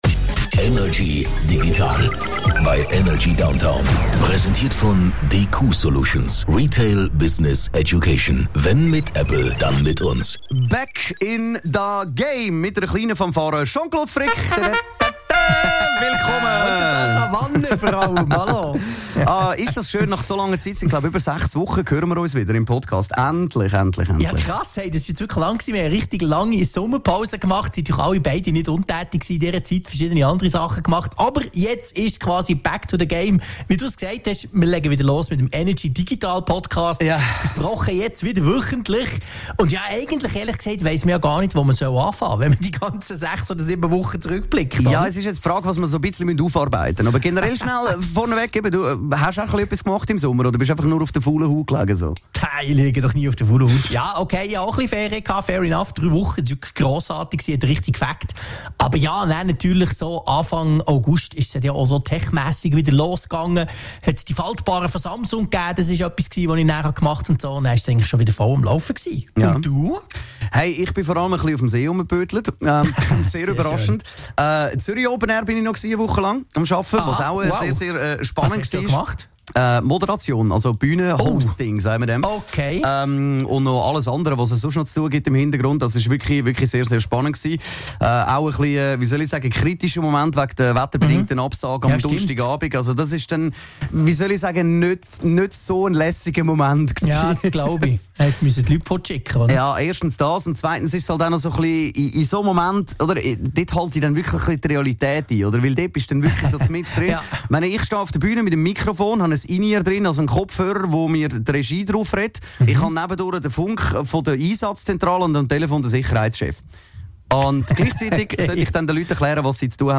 im Energy Studio
aus dem HomeOffice über die digitalen Themen der Woche.